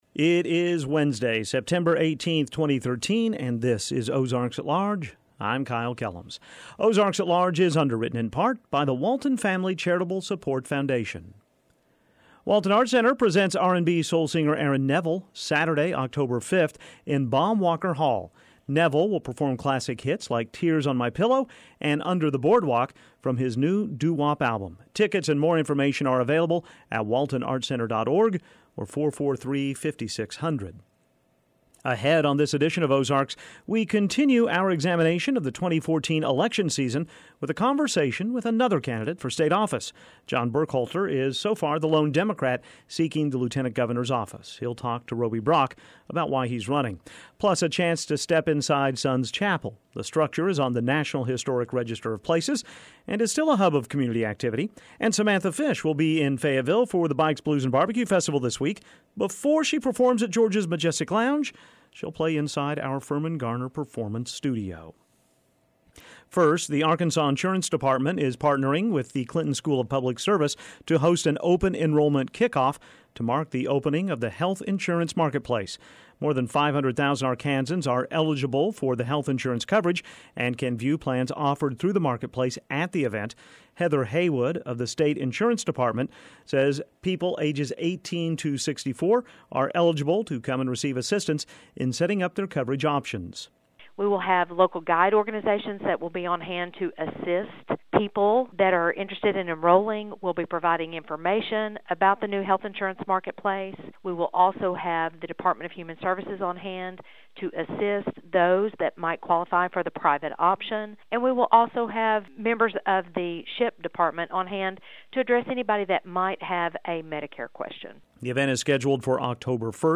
And Samantha Fish will be in Fayetteville for the Bikes, Blues and BBQ festival this week…before she performs at George’s Majestic Lounge, she plays inside our Firmin-Garner Performance Studio.